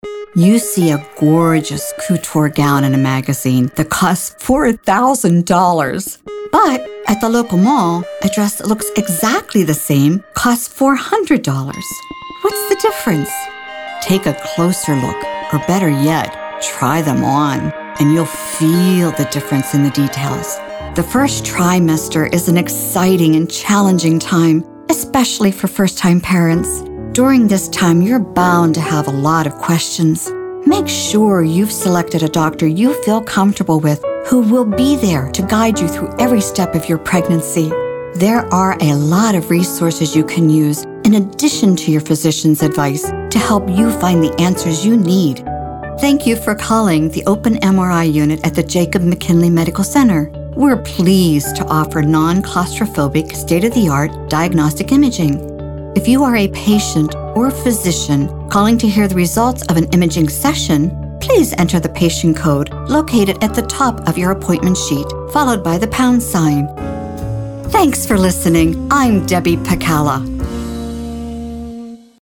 Voiceover Talent